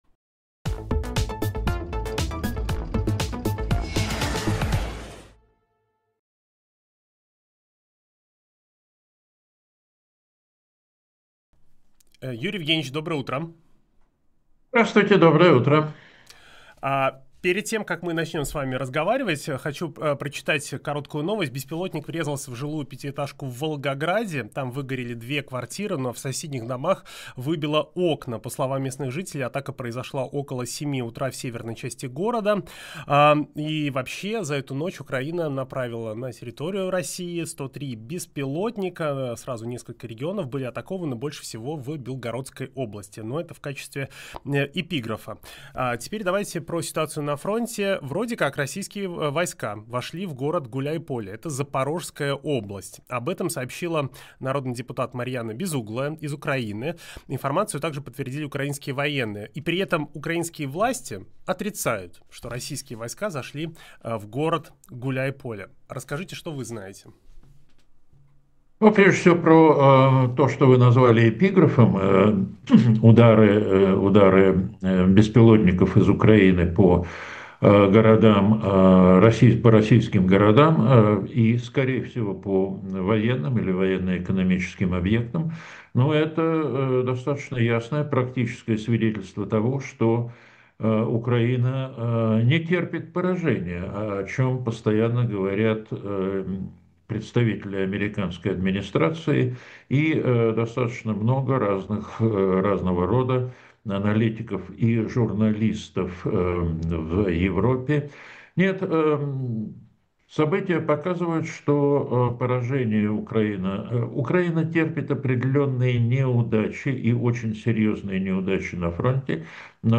Фрагмент эфира от 29.11.25
военно-политический эксперт